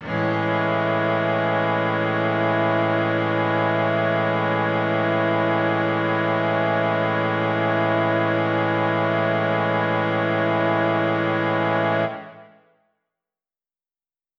SO_KTron-Cello-Amaj7.wav